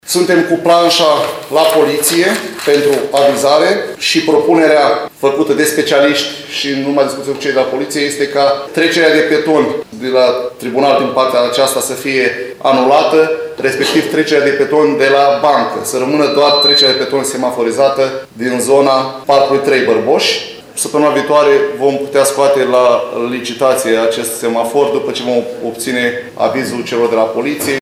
Viceprimarul LUCIAN HARȘOVSCHI a declarat astăzi că prin aceste modificări se dorește fluidizarea traficului rutier pe bulevardul principal.